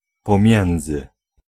Ääntäminen
US : IPA : [bɪ.ˈtwin] UK : IPA : /bɪˈtwiːn/